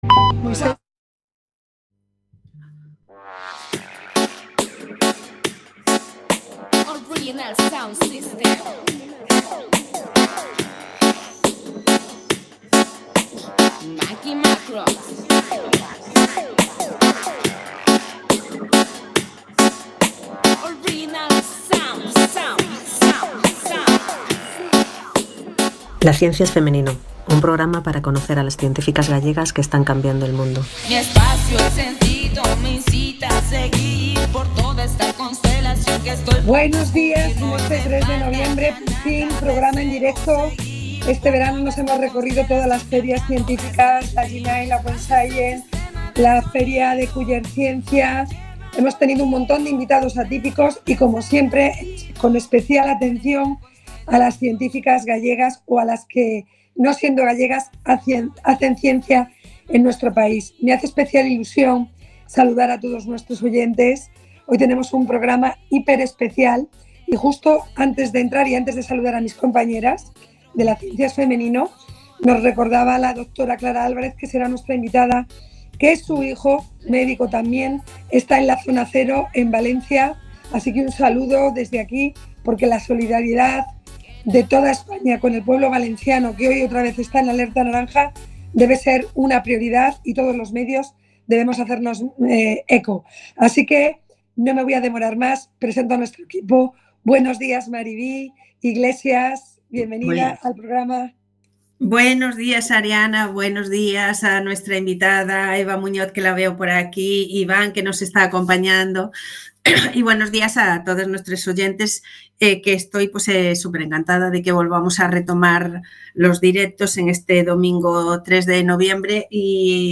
En este programa de radio, cada 15 días, durante una hora, de 12.00 a 13.00, en domingos alternos, Entrevistamos y damos voz a las científicas gallegas que trabajan hoy en la ciencia.
Localizaciones: Estudio José Couso- Cuac FM.